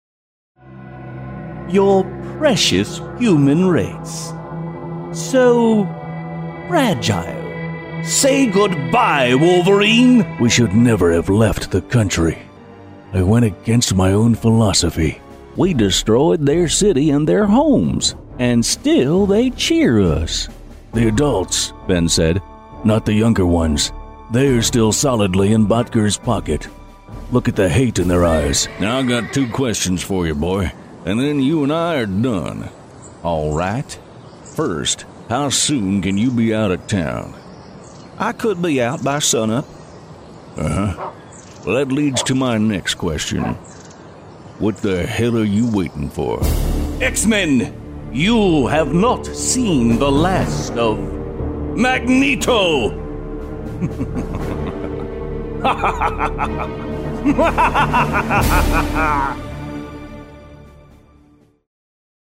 Season voice actor, producer and director as well as character voice specialist.
mid-atlantic
middle west
Sprechprobe: Sonstiges (Muttersprache):